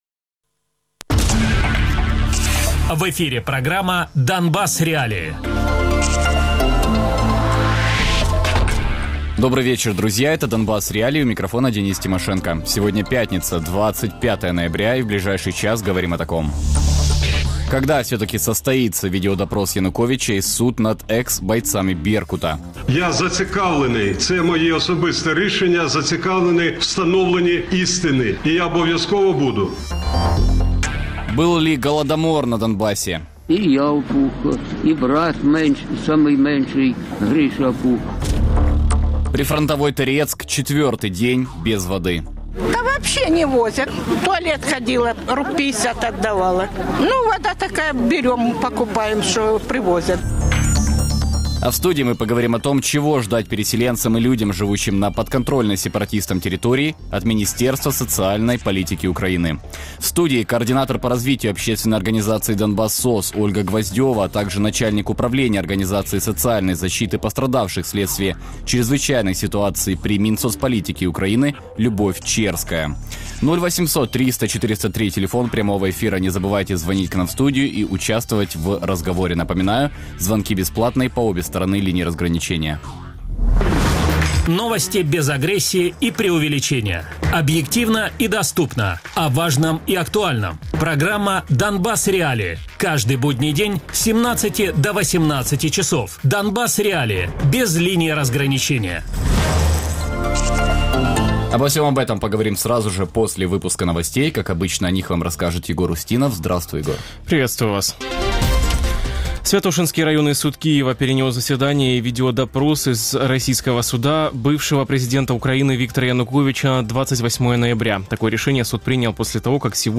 Без агресії і перебільшення. 60 хвилин про найважливіше для Донецької і Луганської областей.